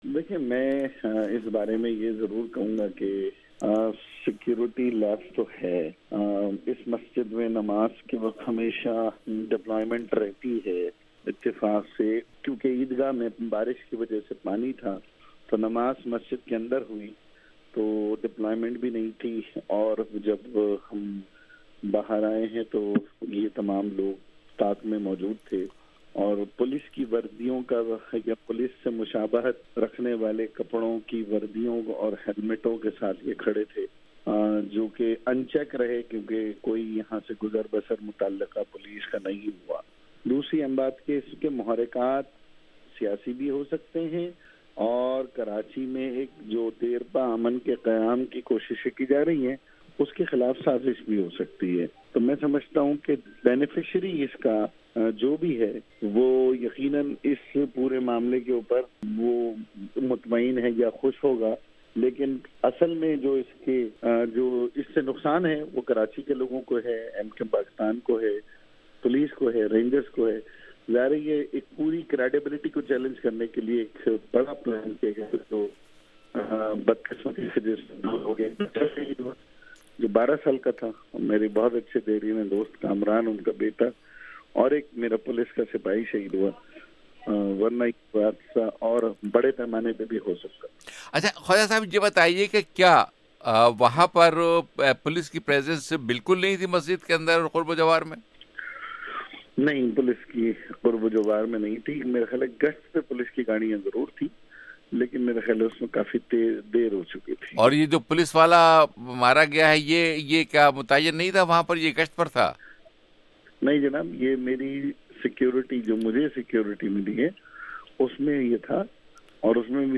قاتلانہ حملے کے بعد خواجہ اظہار کی وائس آف امریکہ سے گفتگو